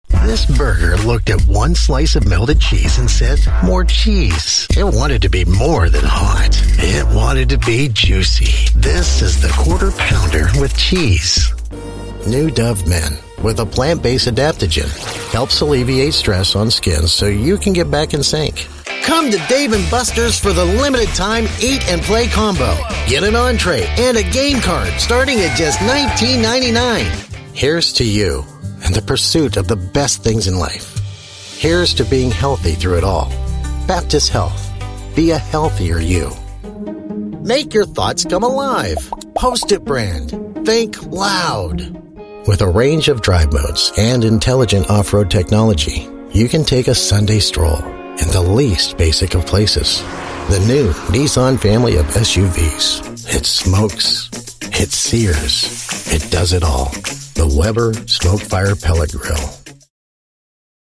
American Voice Over Talent
Adult (30-50) | Older Sound (50+)
The Voice Realm represents professional and affordable American and Canadian voice talent with authentic North American accents suited to international voice castings, from small jobs to international campaigns.
Our voice over talent record in their professional studios, so you save money!